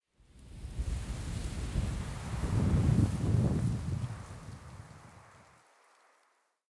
housewind01.ogg